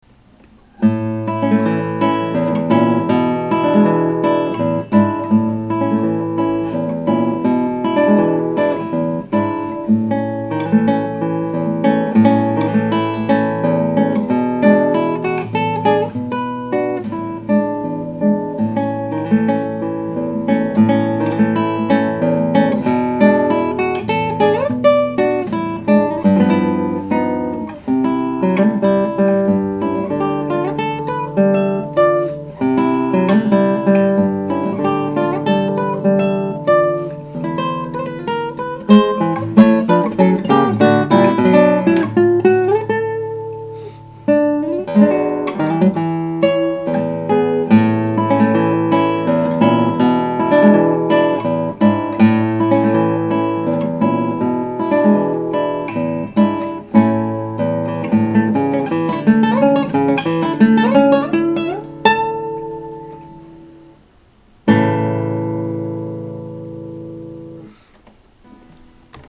Atlanta Guitarist for Hire